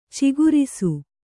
♪ cigurisu'